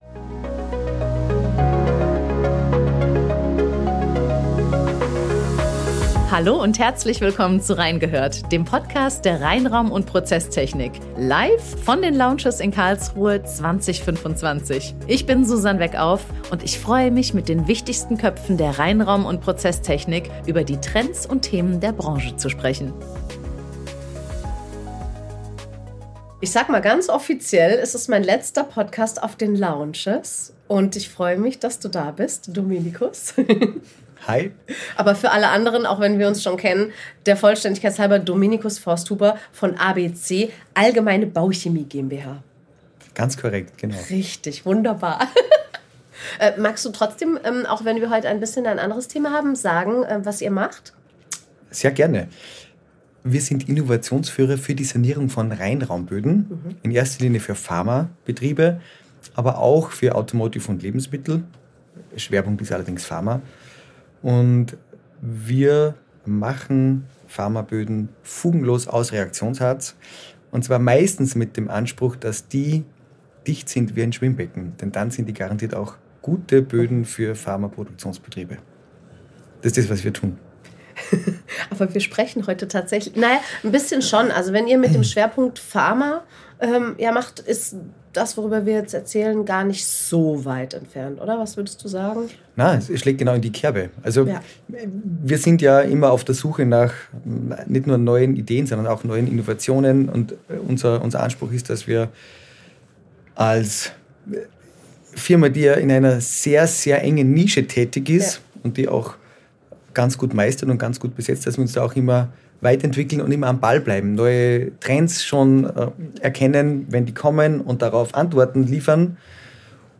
Reingehört spezial, live von den Lounges 2025 in Karlsruhe.